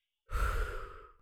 Christmas Sound Effects #1
008 short blow.wav